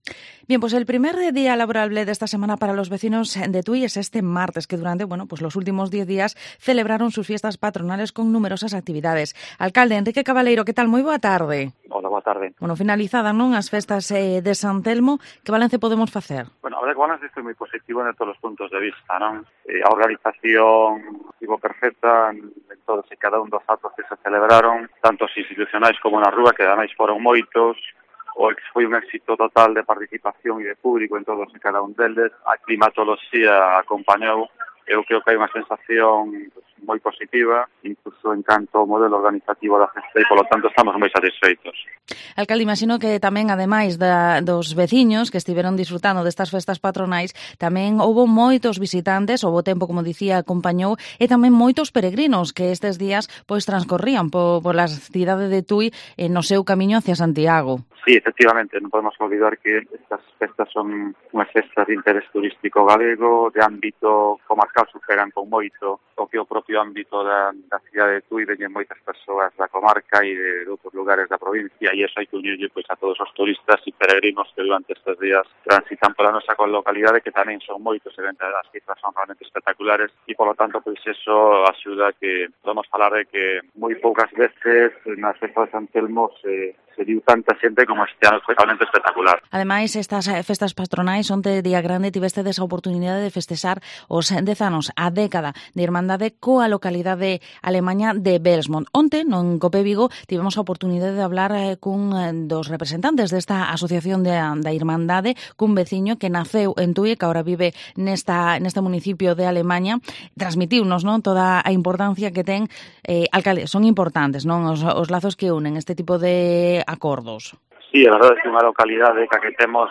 Entrevista al Alcalde de Tui, Enrique Cabaleiro